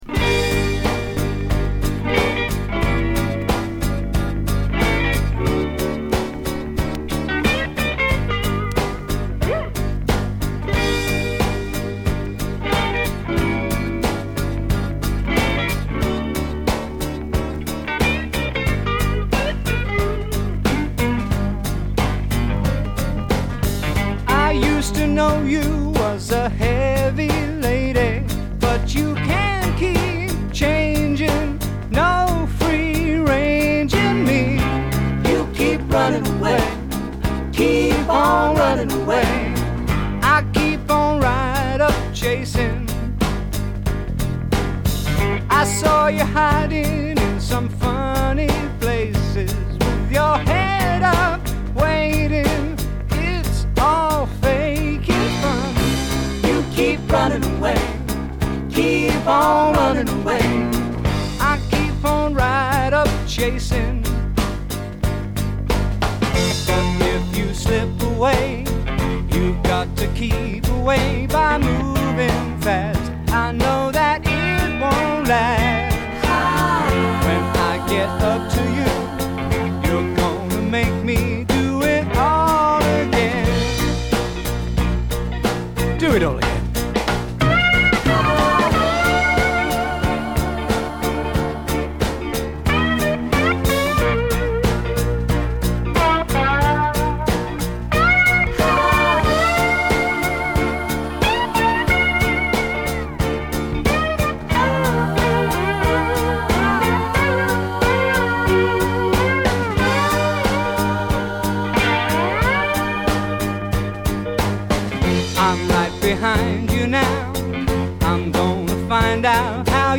Very much in the Southern California 70's sound
vocals, guitar
vocals, keyboards
bass
drums